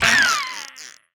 Minecraft Version Minecraft Version snapshot Latest Release | Latest Snapshot snapshot / assets / minecraft / sounds / mob / dolphin / death2.ogg Compare With Compare With Latest Release | Latest Snapshot